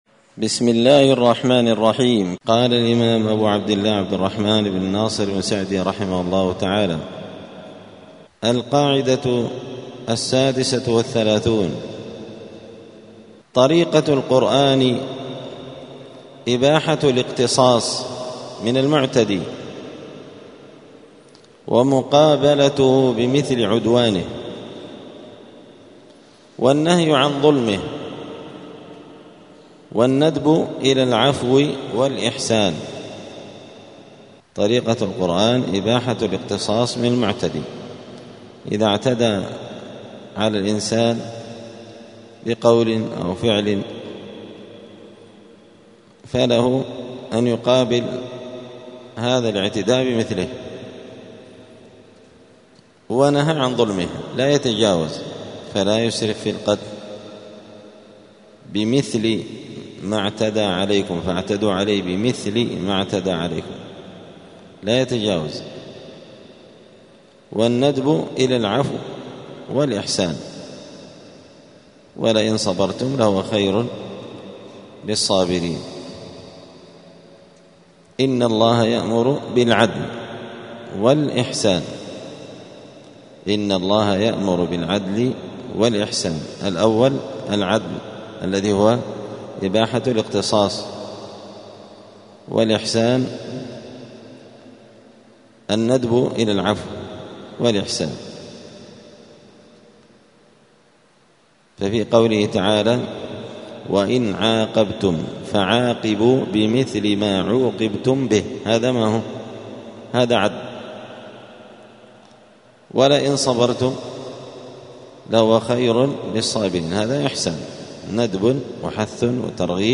دار الحديث السلفية بمسجد الفرقان قشن المهرة اليمن
46الدرس-السادس-والأربعون-من-كتاب-القواعد-الحسان.mp3